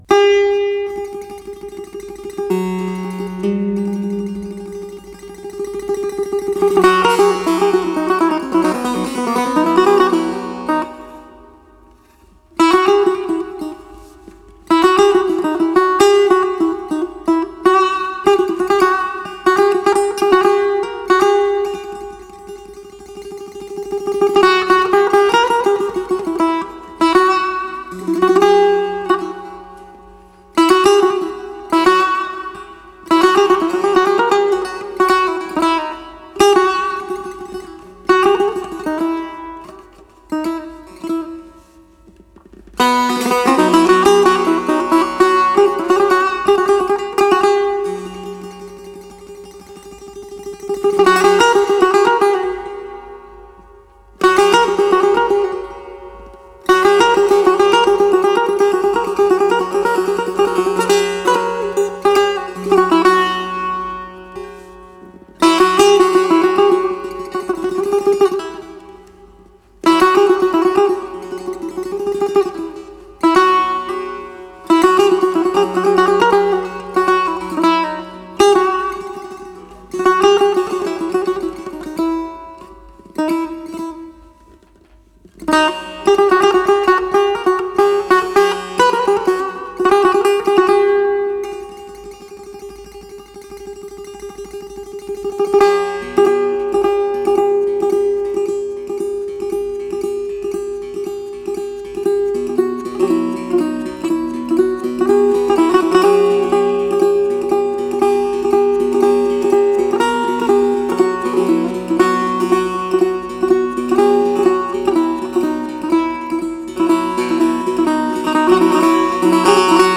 Solo Setar